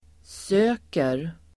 Uttal: [s'ö:ker]